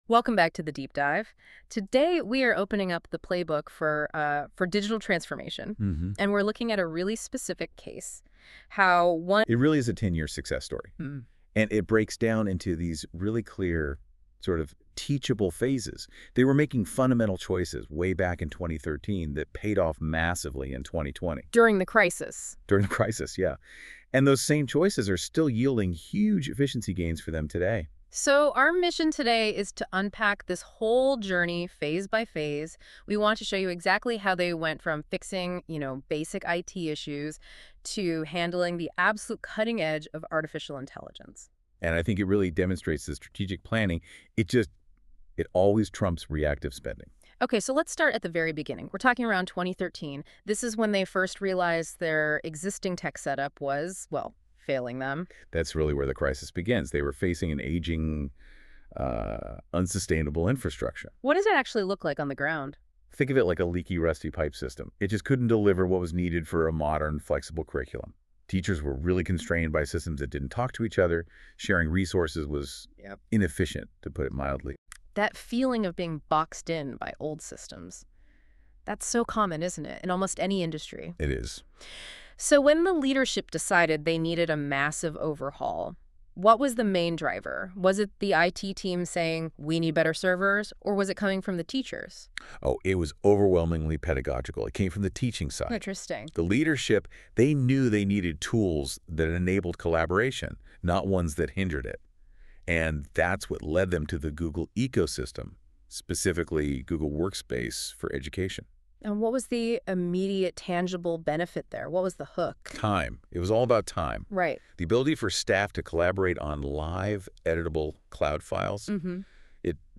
NotebookLM also gave us the ability to make our own podcast on the subject, as well as a video!